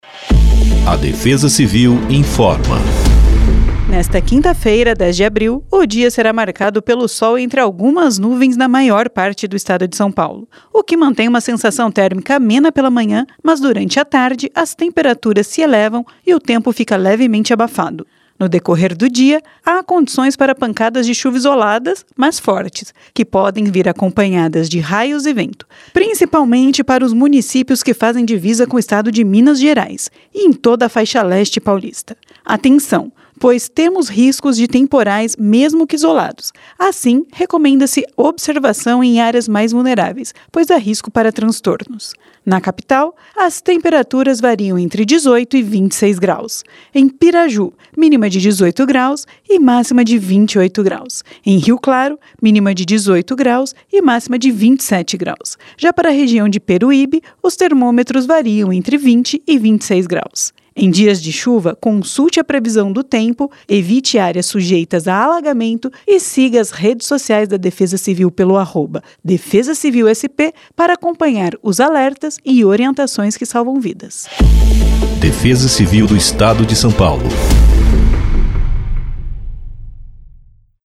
Defesa-Civil-Boletim-Previsao-do-Tempo-para-1004-Spot.mp3